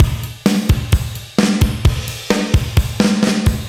Index of /musicradar/80s-heat-samples/130bpm
AM_GateDrums_130-03.wav